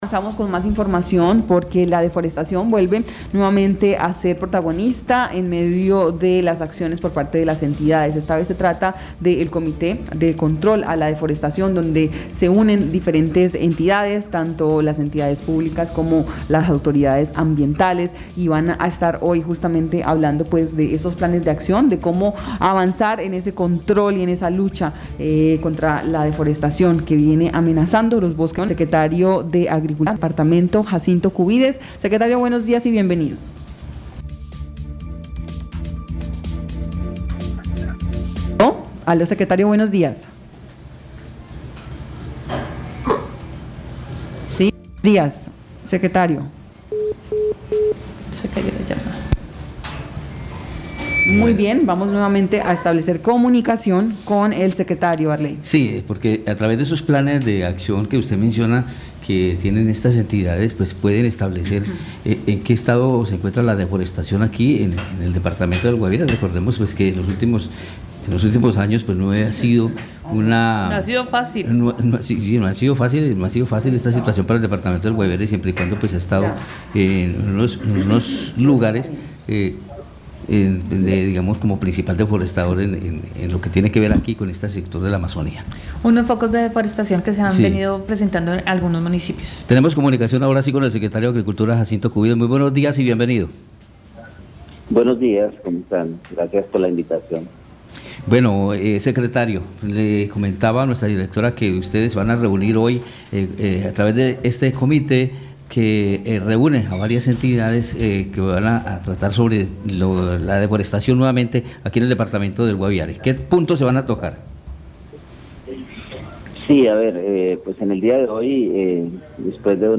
Escuche a Jacinto Cubides, secretario de Agricultura del Guaviare.